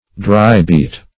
\Dry"-beat`\